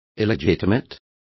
Complete with pronunciation of the translation of illegitimate.